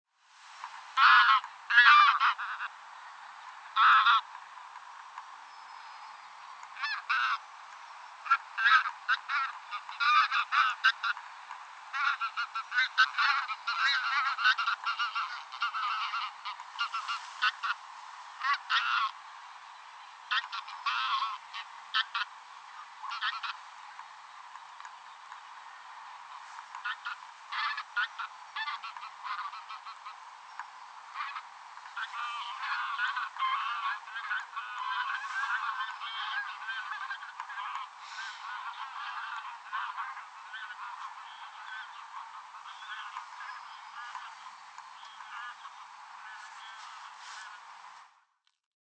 Grauwe gans
Hun gakken is luid en waarschuwt andere ganzen.
Hun roep is laag en rollend, iets heel bijzonders.
De roep van de grauwe gans heeft een lage, rollende klank die je direct opmerkt.